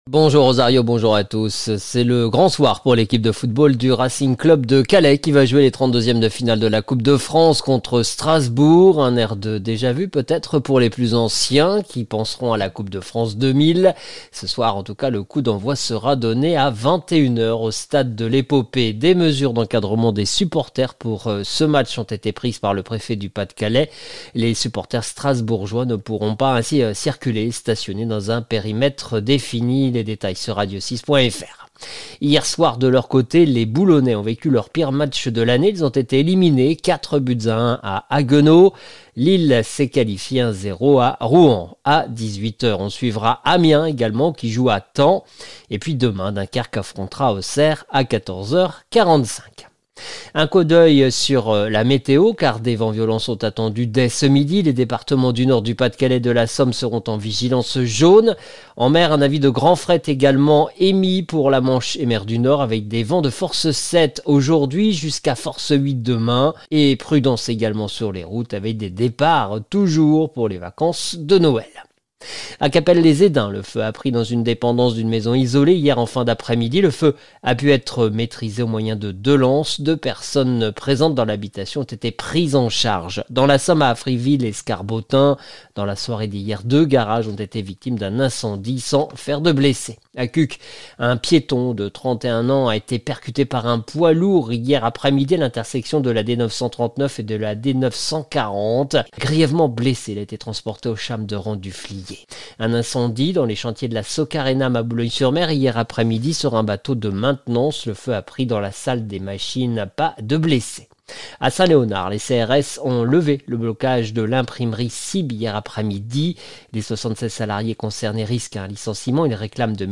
Le journal du samedi 21 décembre 2024